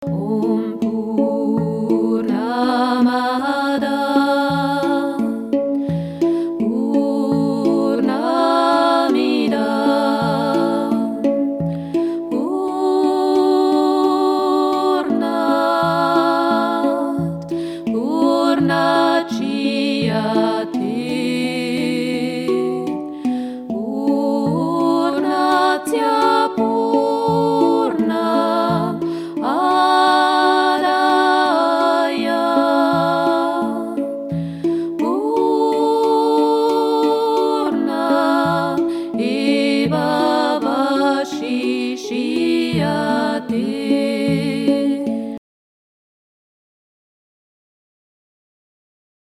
Indisches Mantra